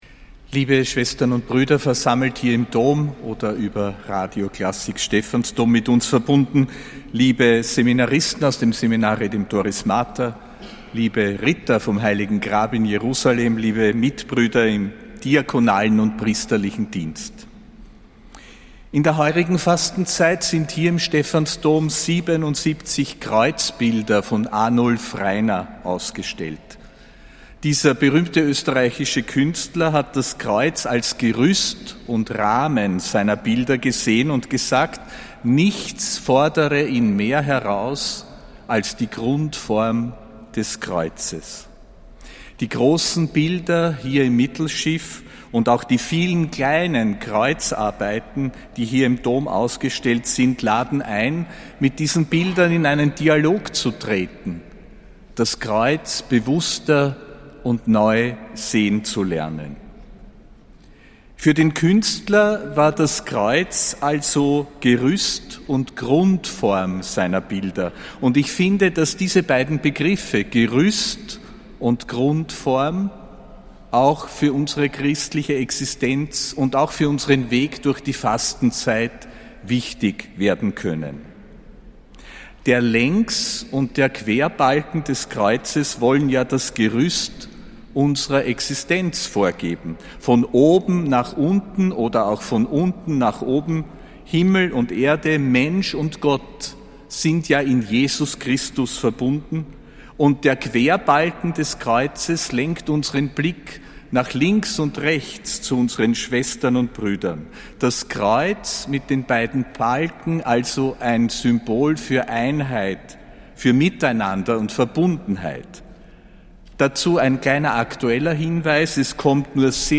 Predigt von Erzbischof Josef Grünwidl zum Aschermittwoch, am 18.